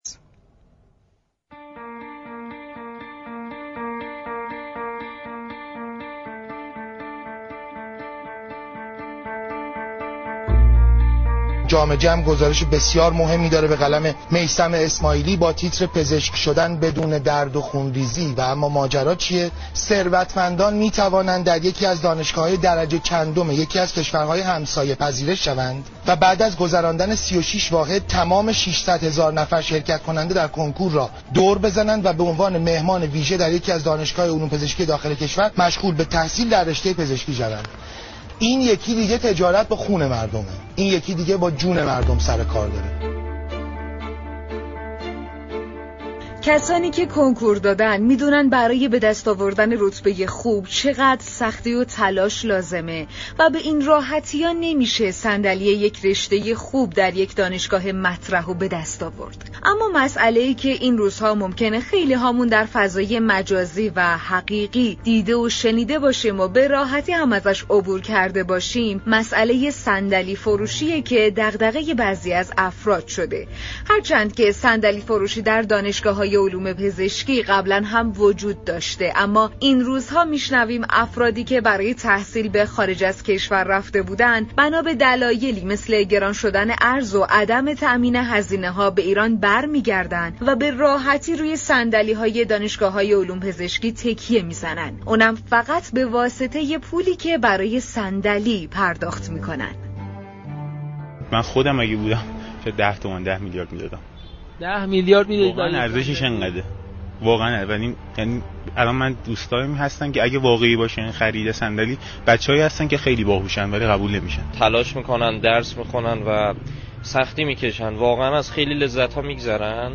محمدرضا احمدی، عضو كمیسیون آموزش و تحقیقات مجلس شورای اسلامی در برنامه تهران كلینیك رادیو تهران درباره دانشجویان وارداتی كه می‌توانند با مجوز وزارت بهداشت یك صندلی در دانشگاه‌های علوم پزشكی داخل كشور را اشغال كنند گفت: این اقدام در حق دانشجویان زحمتكش و تلاشگر، ظلم بزرگی است.